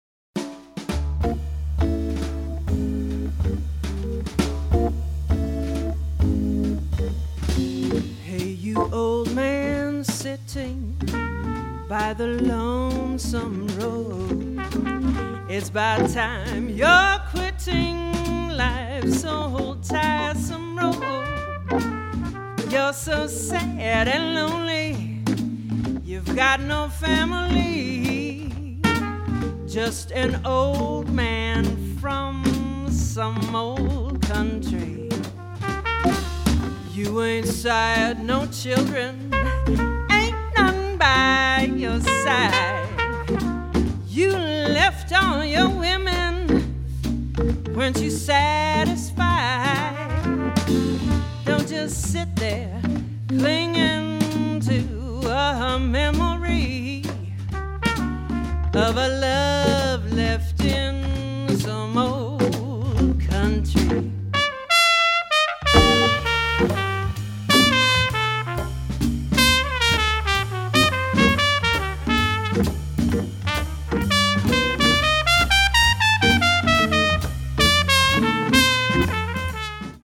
tenor and baritone saxes, vocals
trumpet
drums
percussion
Melvin Rhyne – hammond B3 organ